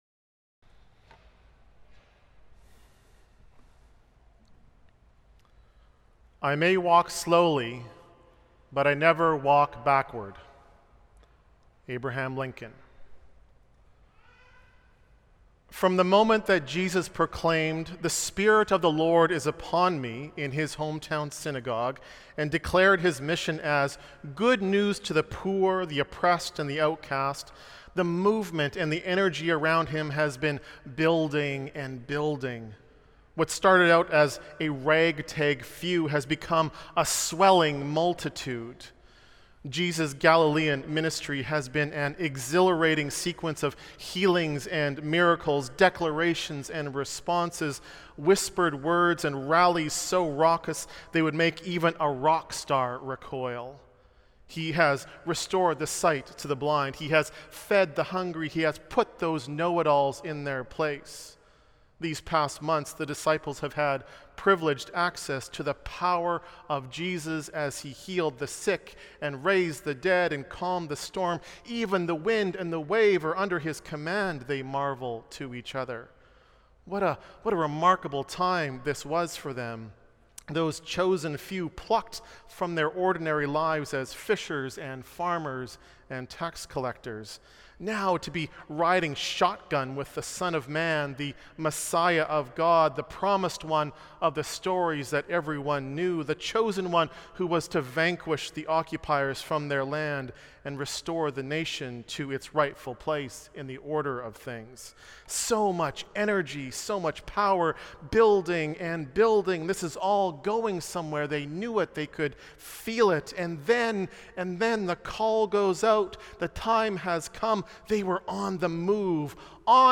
Our Pride Service features participation from Met’s Affirm committee and lots of celebratory music from the Choir, Great Heart, and Choristers - including a uniquely Metropolitan interpretation of Lady Gaga’s Born This Way.
Sermon Notes